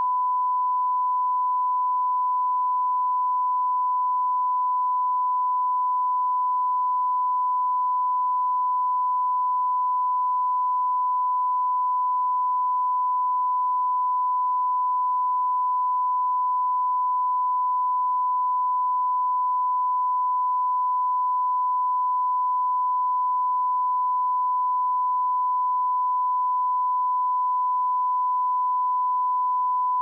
TONE.wav